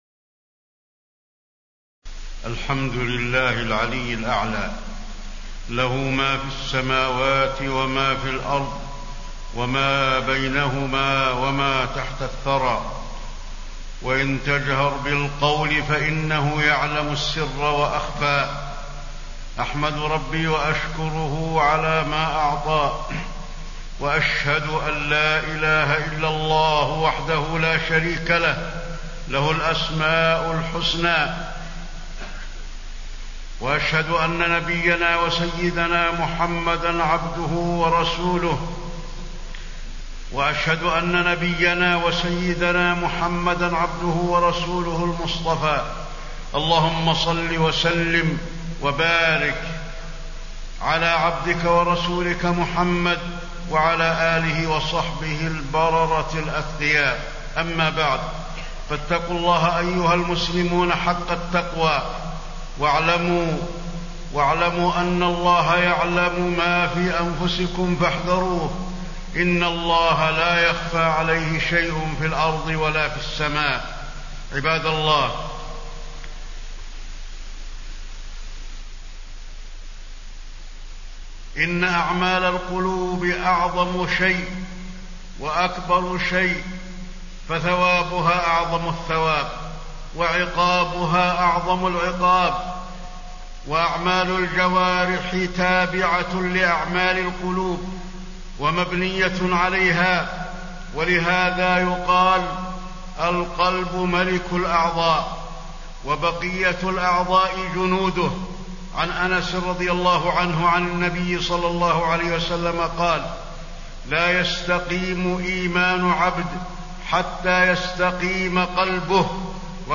تاريخ النشر ٨ صفر ١٤٣٤ هـ المكان: المسجد النبوي الشيخ: فضيلة الشيخ د. علي بن عبدالرحمن الحذيفي فضيلة الشيخ د. علي بن عبدالرحمن الحذيفي الخوف من الله وأثره في حياة المسلم